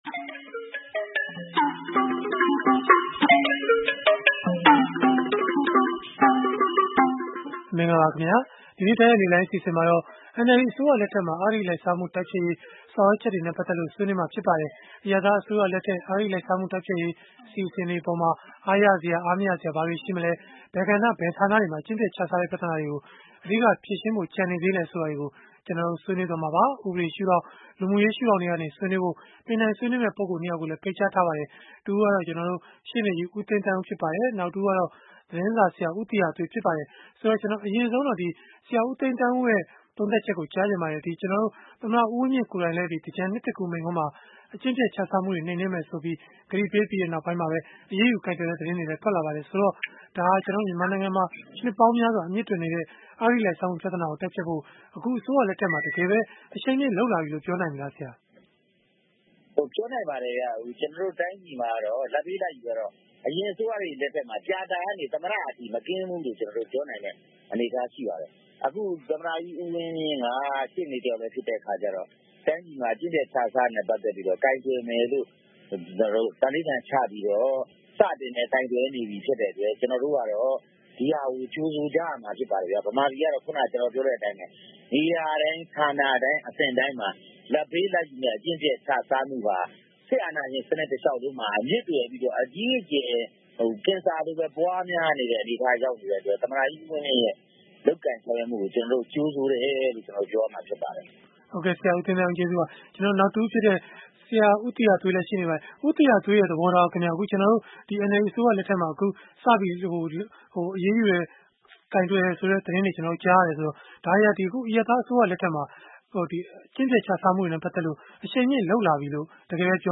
ဗွီအိုအေရဲ့ စနေနေ့ည တိုက်ရိုက်လေလှိုင်း အစီအစဉ်မှာ